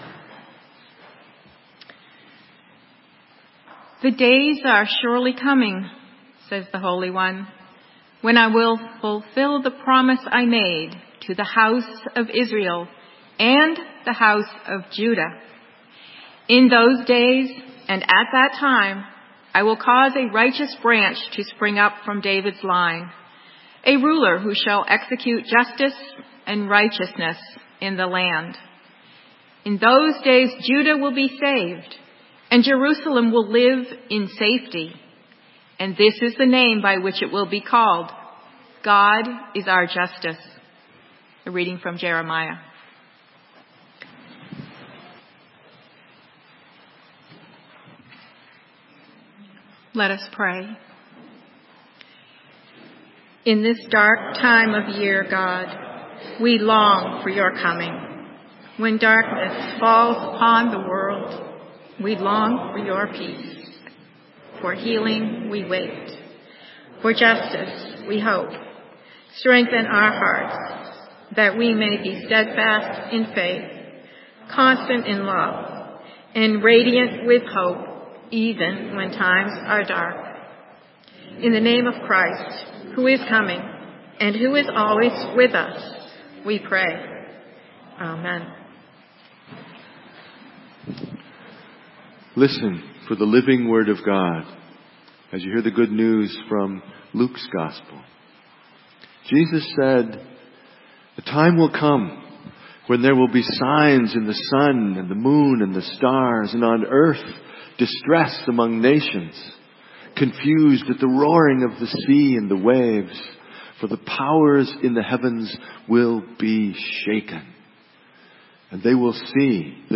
Sermon:Deepen your longing - St. Matthew's UMC